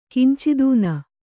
♪ kincidūna